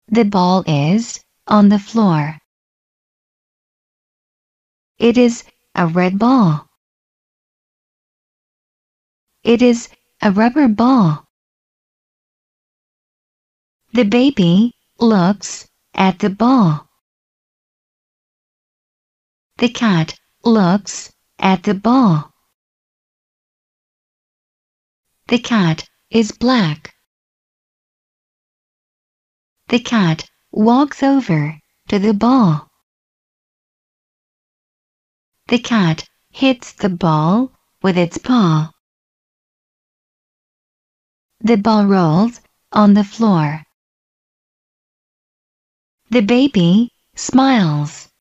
— mp-3 — средняя скорость звучания;
Второе аудио носители английского языка озвучивают два раза и со средней скоростью.